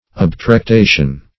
obtrectation - definition of obtrectation - synonyms, pronunciation, spelling from Free Dictionary
Search Result for " obtrectation" : The Collaborative International Dictionary of English v.0.48: Obtrectation \Ob`trec*ta"tion\, n. [L. obtrectatio, from obtrectare to detract from through envy.